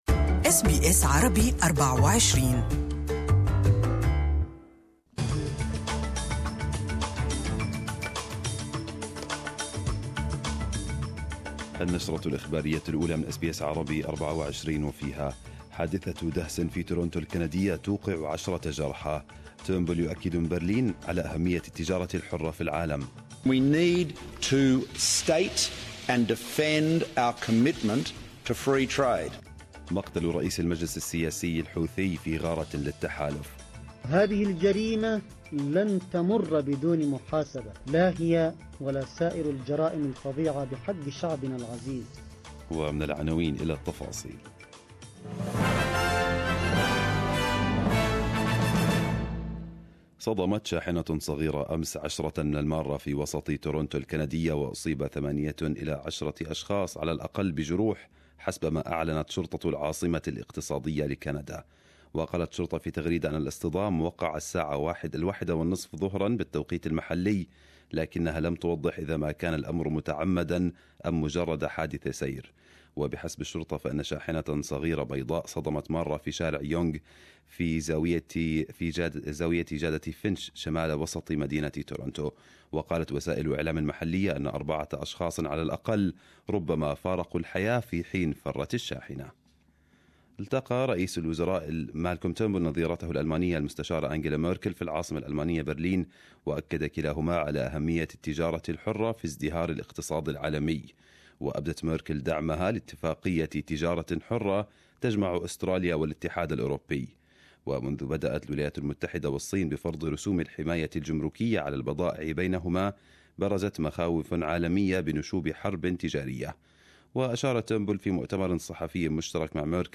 Arabic News Bulletin 24/04/2018